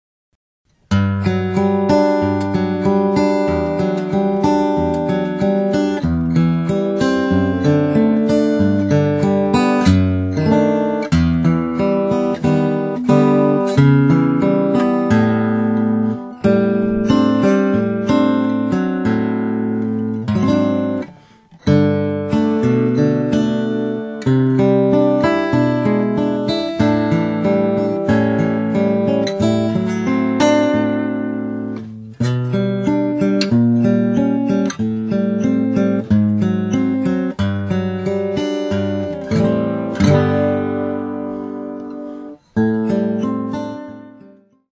a nice vocal accompaniment version of this classic hymn